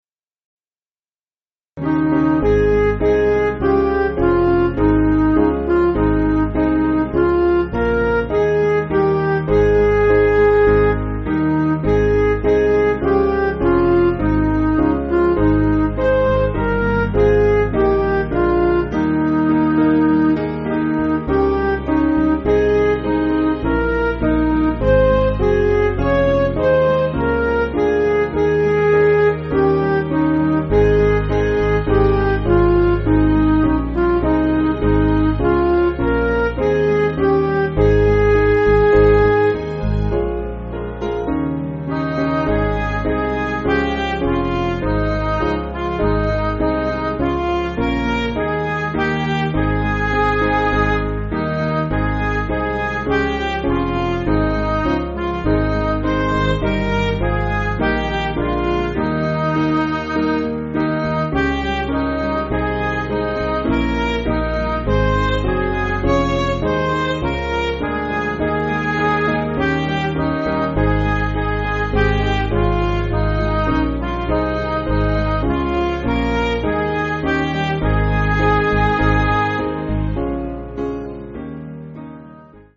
Piano & Instrumental
(CM)   4/Ab
Midi